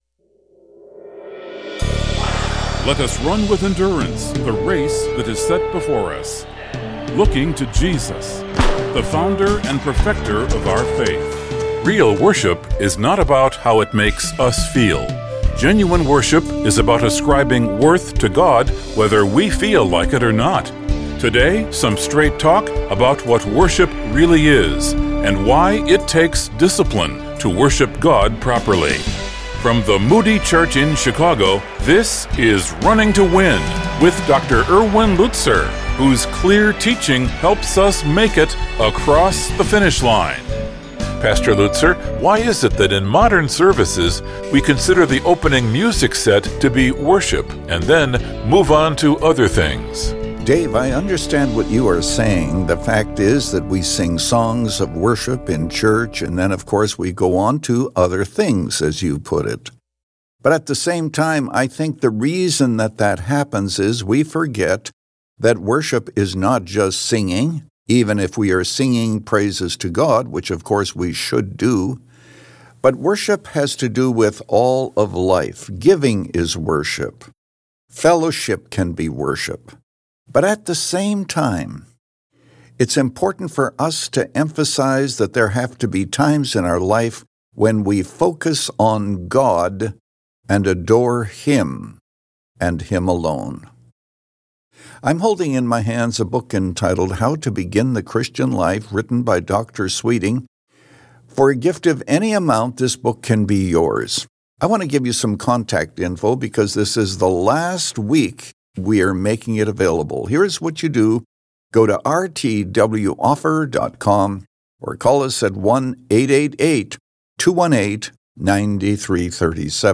So how does worship become a transforming experience? In this message from Hebrews 9